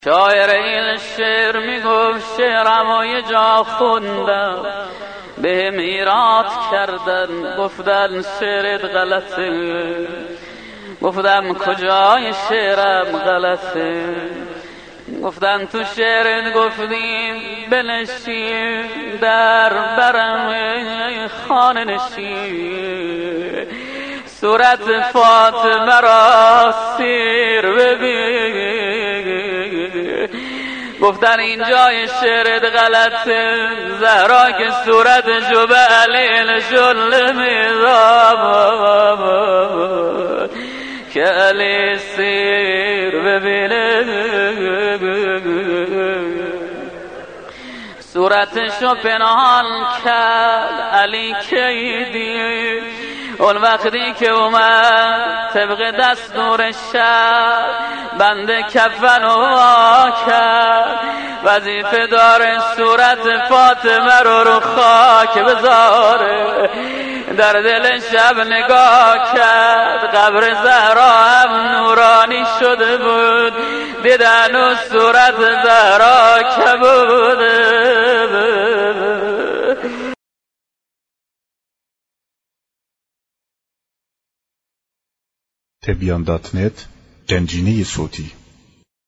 روضه خوانی در مقام حضرت زهرا (س)